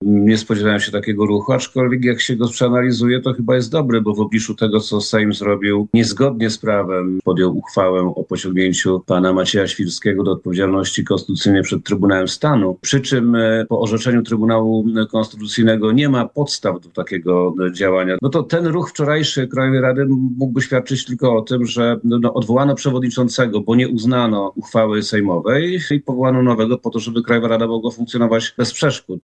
– Odwołanie Macieja Świrskiego z funkcji przewodniczącego Krajowej Rady Radiofonii i Telewizji to sprawa wewnętrzna KRRiT i jest dla mnie lekkim zaskoczeniem – powiedział gość porannej rozmowy Radia Lublin, poseł i wiceprezes Prawa i Sprawiedliwości, Przemysław Czarnek.